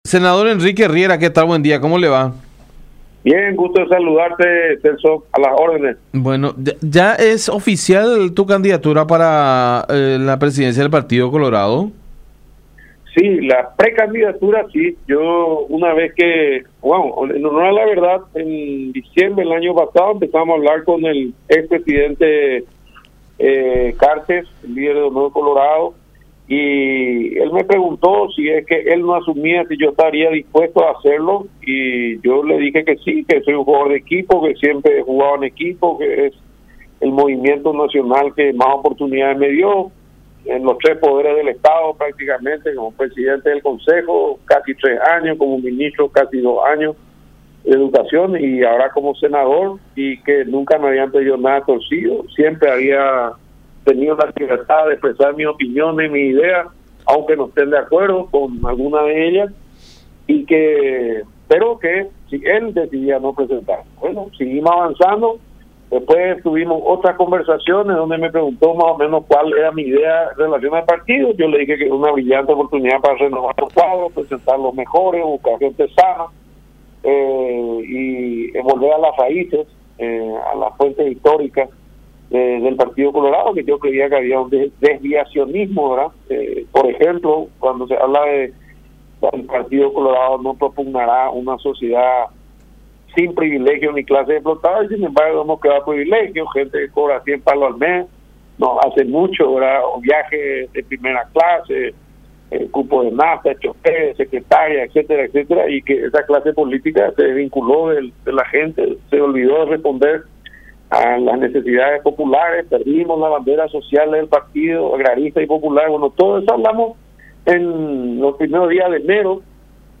Y le dije que sí”, expresó Riera en contacto con La Unión.